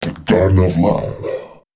038 male.wav